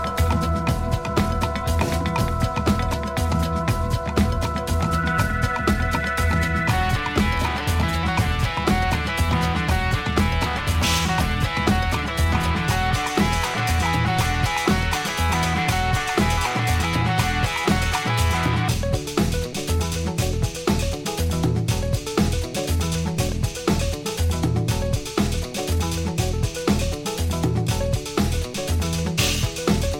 パート1となるこちらはギター、キーボード、ドラム、エレクトロニクスが巧みに絡み合う全7曲を収録。